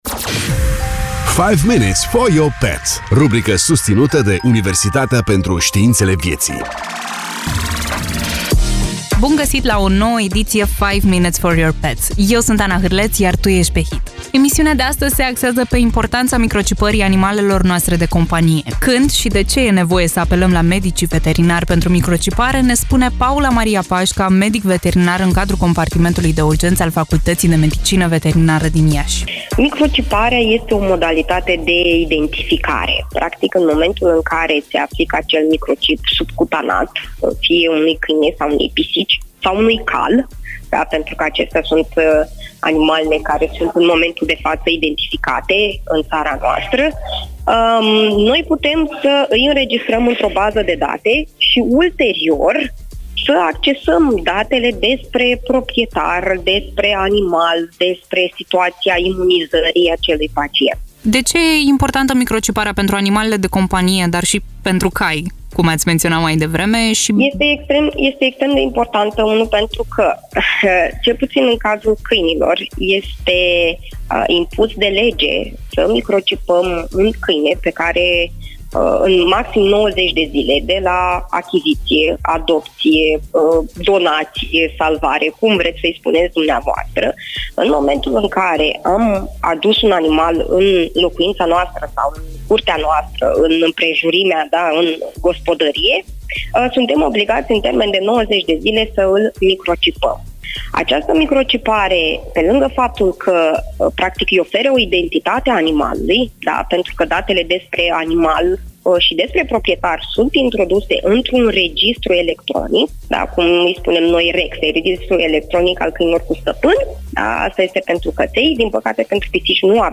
în dialog